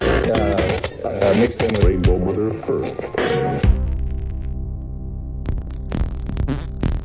old school dj samples ep